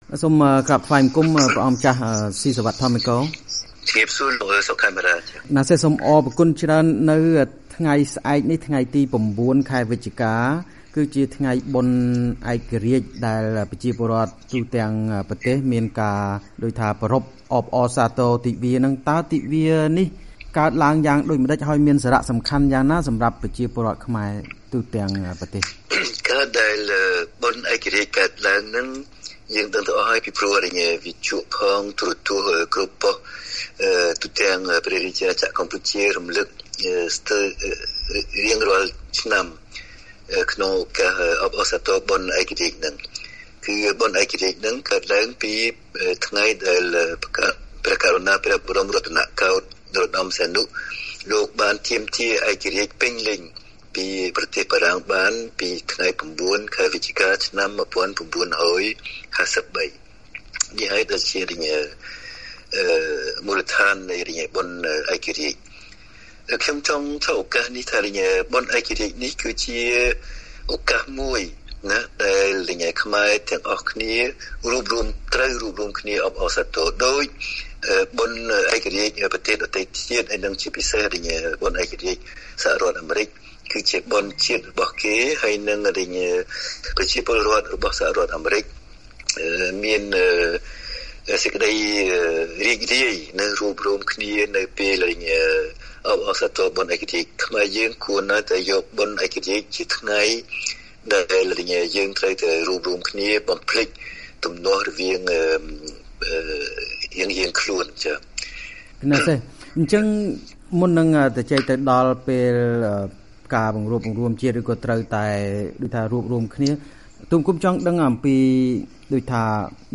បទសម្ភាសន៍ VOA៖ អតីតលេខាសម្តេចឪបានបញ្ជាក់ពីអត្ថន័យនៃពិធីបុណ្យឯករាជ្យ៩វិច្ឆិកានិងការបង្រួបបង្រួមជាតិ